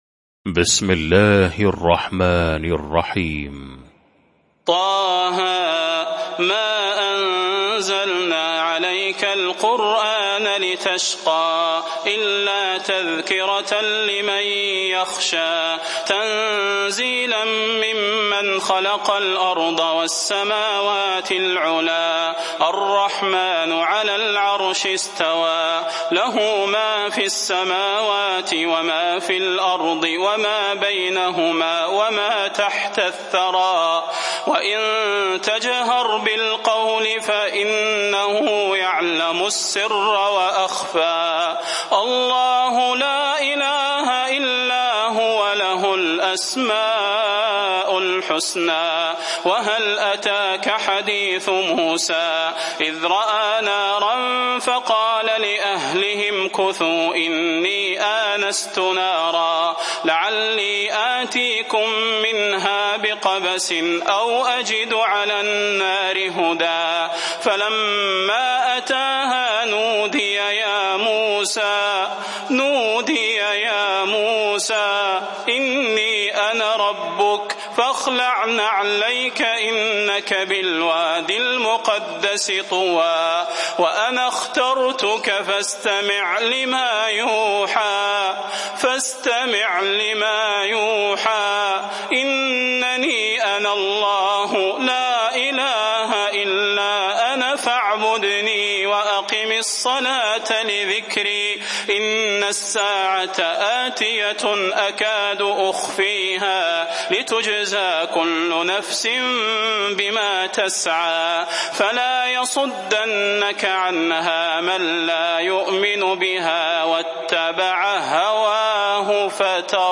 المكان: المسجد النبوي الشيخ: فضيلة الشيخ د. صلاح بن محمد البدير فضيلة الشيخ د. صلاح بن محمد البدير طه